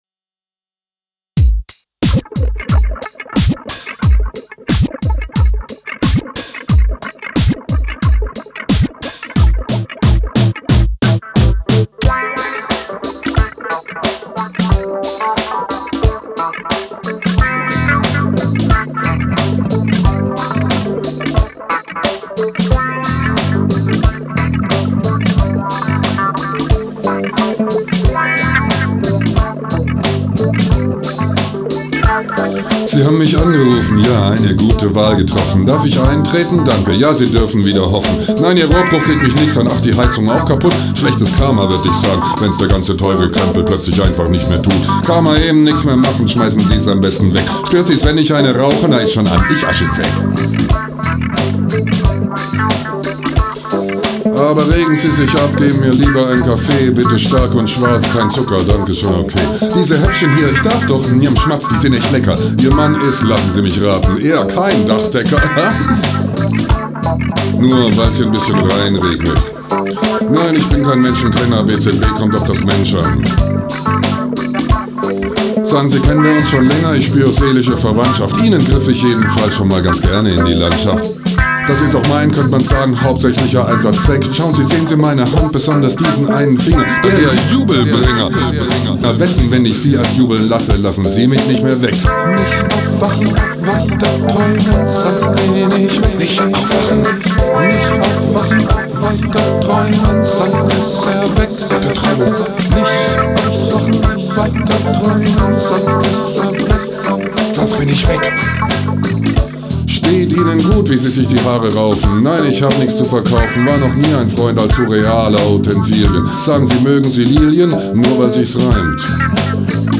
Skizze für „february album writing month“ FAWM2015: Schlagzeugmaschine, E-Gitarren, E-Bass, Gesang, E-Piano